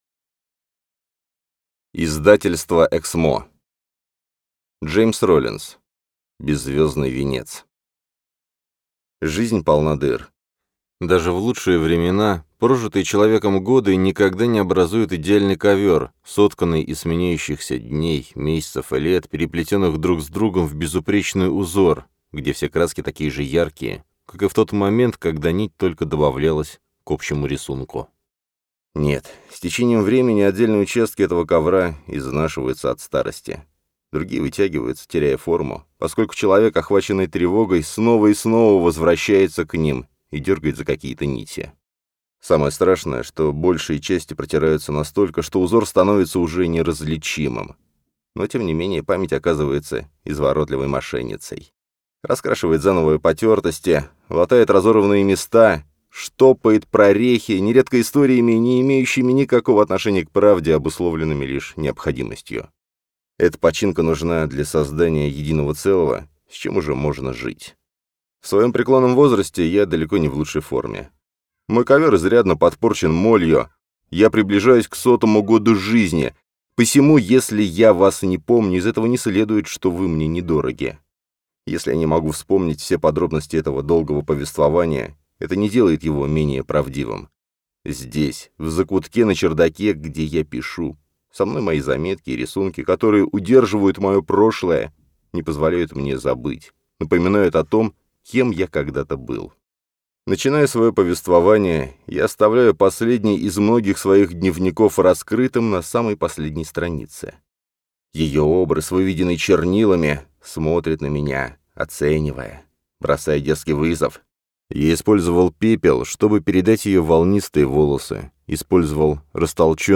Аудиокнига Беззвездный Венец | Библиотека аудиокниг